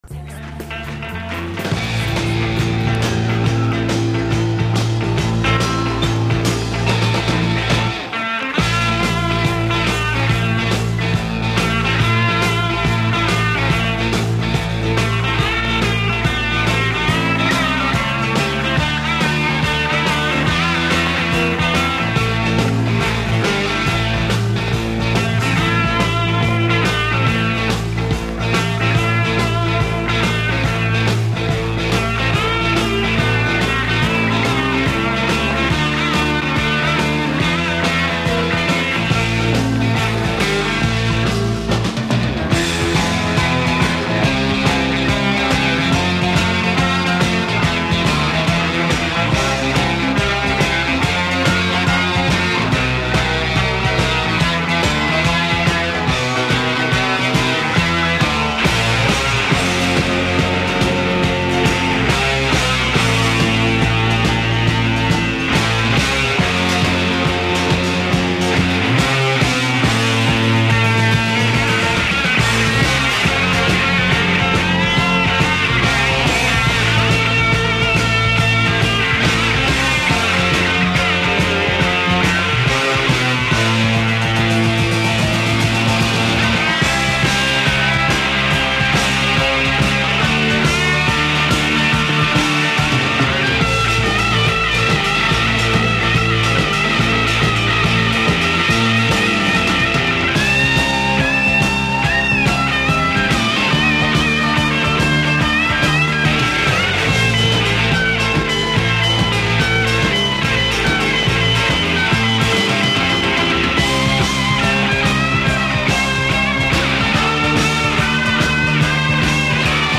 [Live]